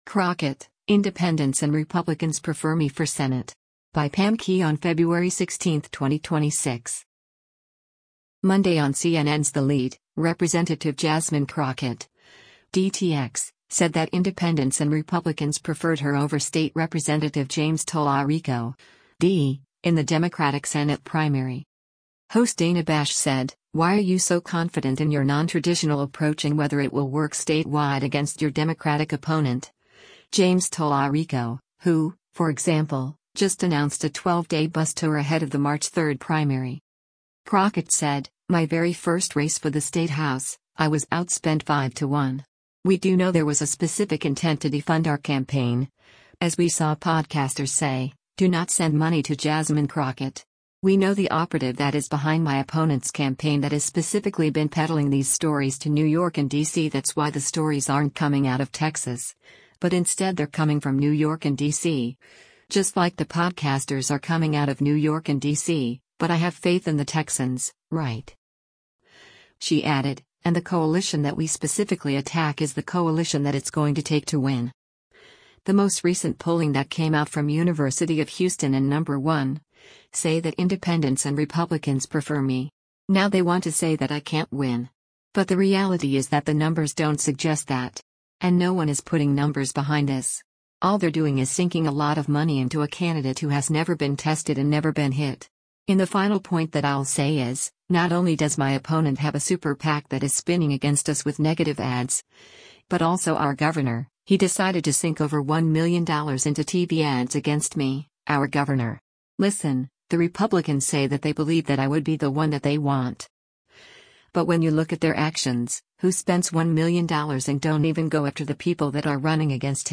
Host Dana Bash said, “Why are you so confident in your nontraditional approach and whether it will work statewide against your Democratic opponent, James Talarico, who, for example, just announced a 12-day bus tour ahead of the March 3rd primary?”